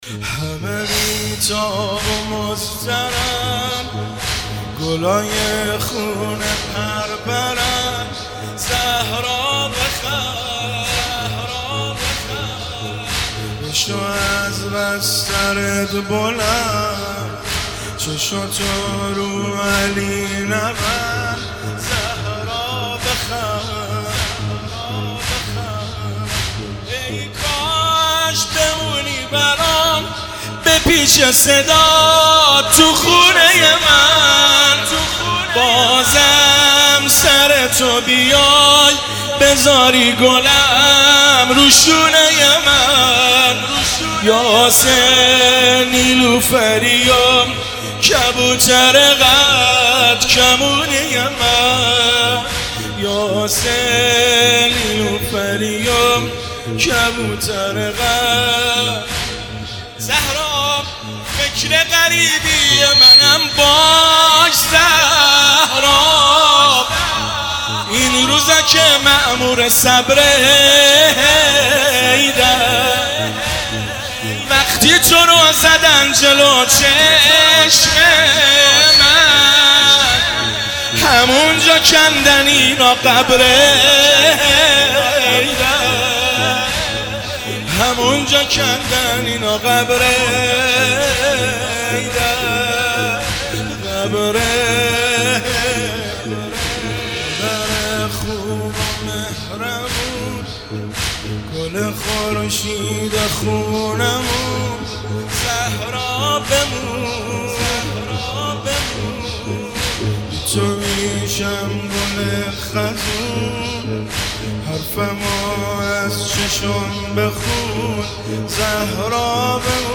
زمینه شب اول فاطمیه اول 1400